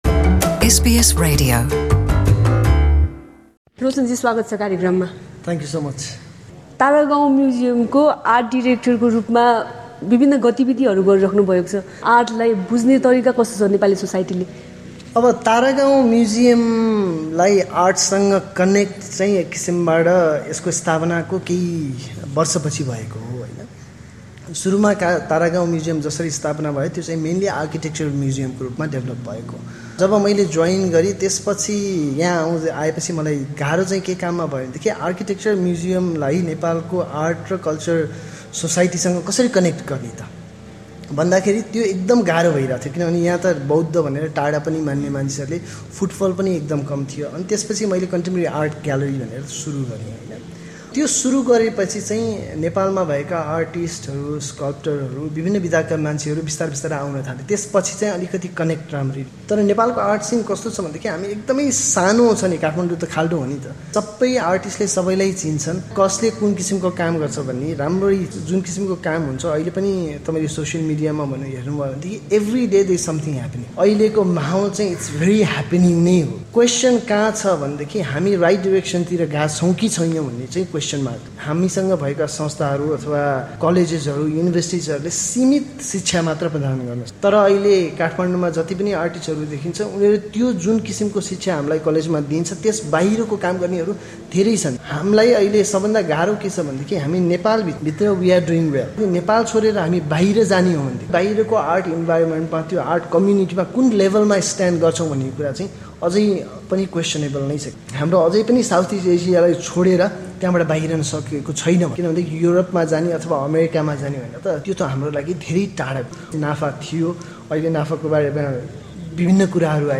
यसै सन्दर्भमा रहेर उनीसँग नेपाली आर्ट, म्युजियम र ग्यालरीबारे एसबिएस नेपालीले गरेको कुराकानी ।